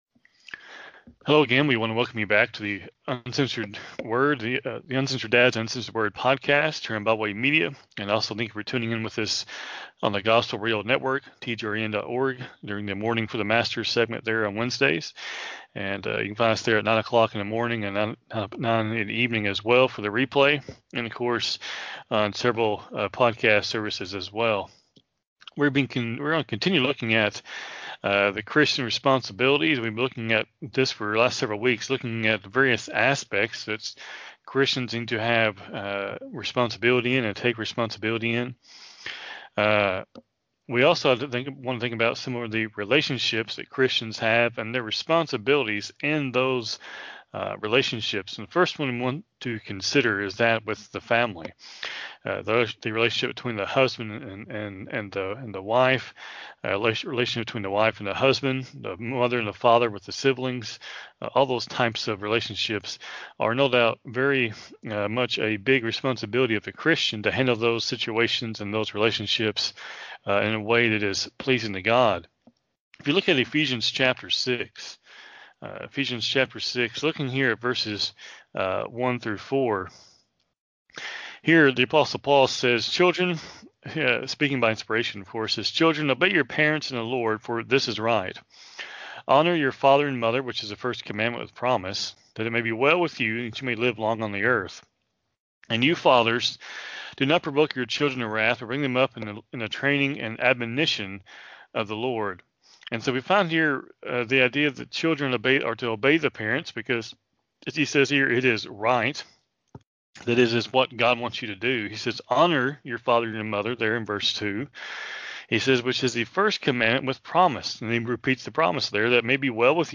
Live program from the TGRN studio in Mount Vernon, TX